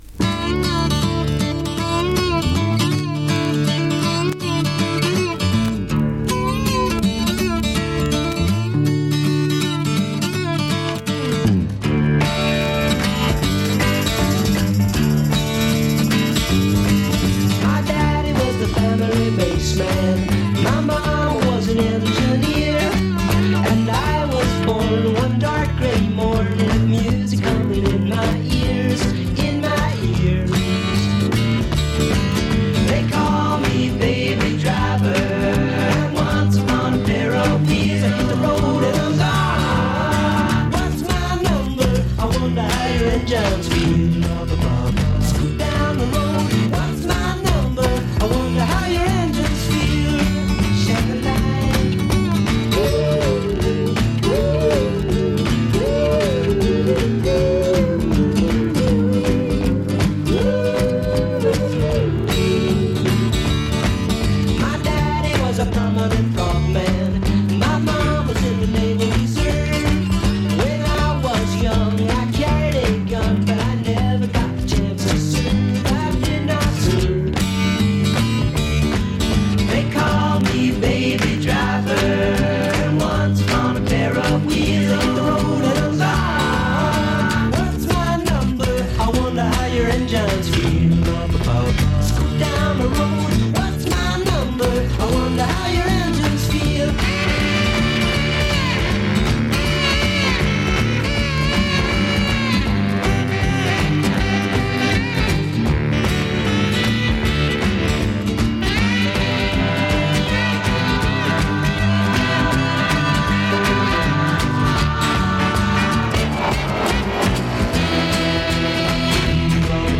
アンプのヘッドフォン・ジャックにICレコーダーをつないでお気楽に録音したものです。
もうひとつ気づいたのですが，終わりの自動車レースに関するアナウンス（？）がシングル盤のほうが良く聞こえます。
3番の歌の途中、サビの前に、エンジン吹かし音が2つ、入っています。
確かにエンディングが上がりません。
エンジン吹かし音が２つ入っています。